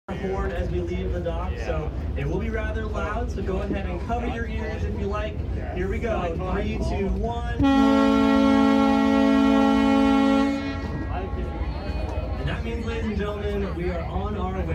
The Fourier transform of the ship’s horn sounding is shown above, as recorded on my Smartphone. The minor peak at 60 Hz is from either or both of the auxiliary power generators. A pattern of spectral peaks occurs at 220 Hz with integer multiples, which is an “A” note on the musical scale. Another pattern begins at 273.5 with integer multiples, just shy of a “C#” note. The presence of these two patterns indicates that the horn was actually a dual horn with two side-by-side horns.
Horn Audio Recording
Salish_horn.mp3